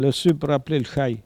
Mémoires et Patrimoines vivants - RaddO est une base de données d'archives iconographiques et sonores.
Il crie pour appeler le chien ( prononcer le cri )